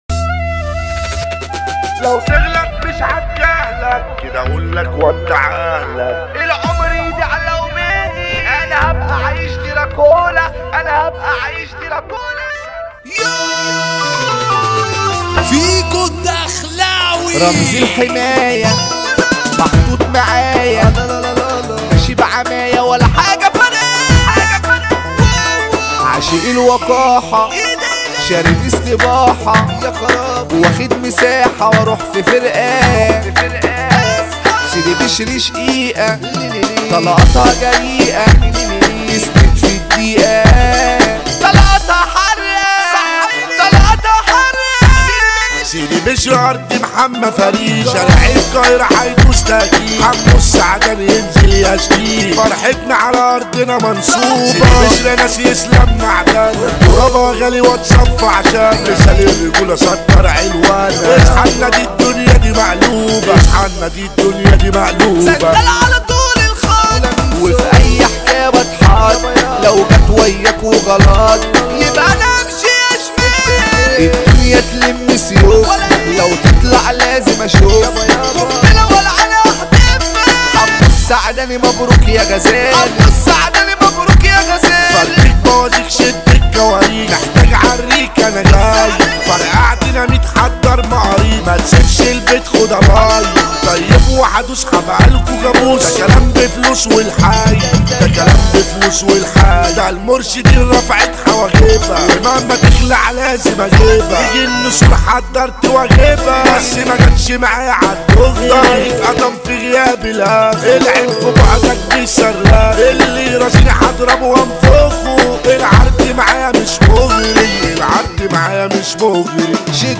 اغاني مصرية